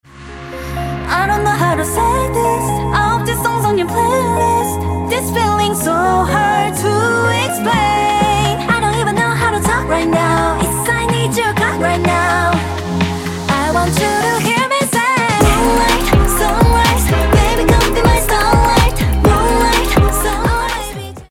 южнокорейской гёрл-группы